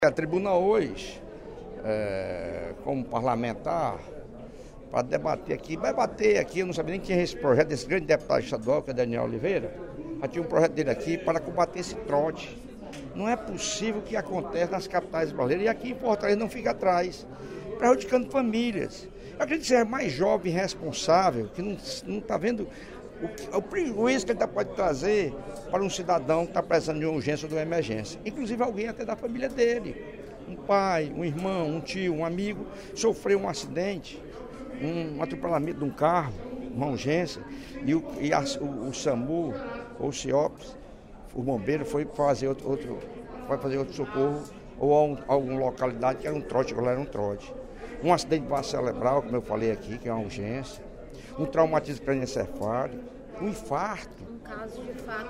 O deputado Lucílvio Girão (PP) chamou a atenção, no primeiro expediente da sessão plenária da Assembleia Legislativa desta quinta-feira (22/06), para o número de trotes que a central de atendimento do Serviço de Atendimento Móvel Urgente (Samu) recebe no Ceará.